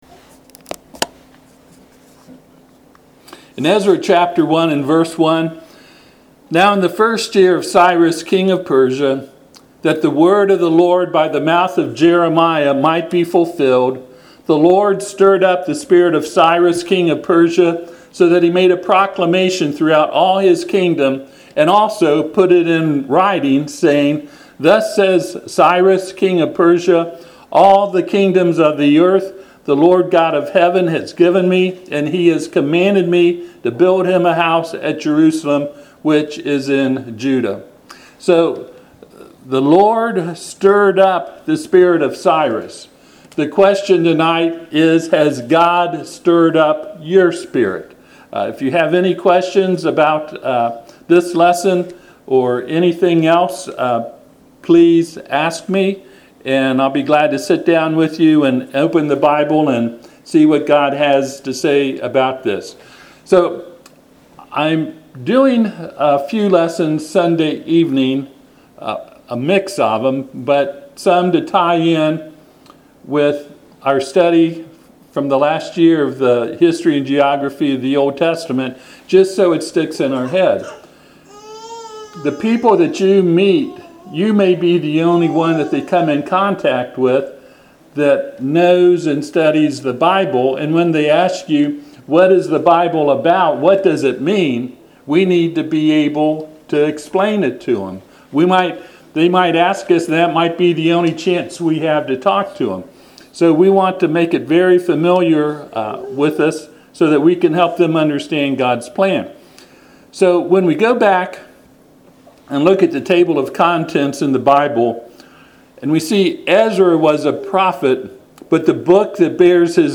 Passage: Ezra 1:1-11 Service Type: Sunday PM